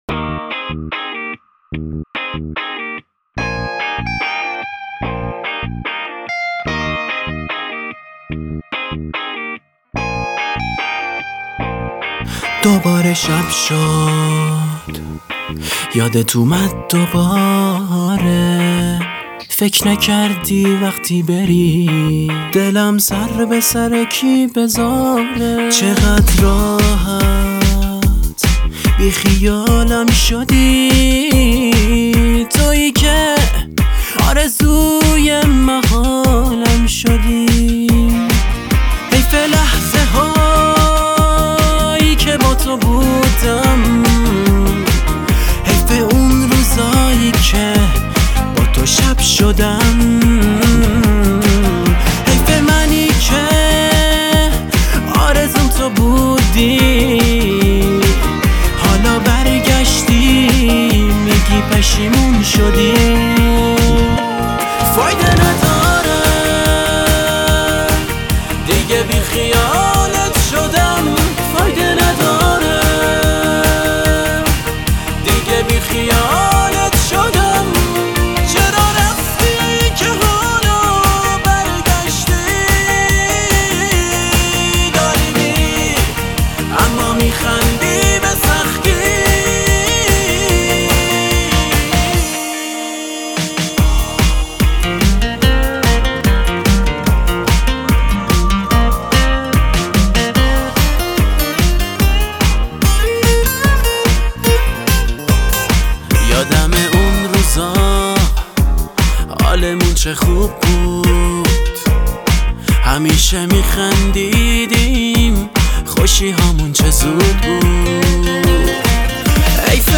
گیتار آکوستیک و گیتار الکتریک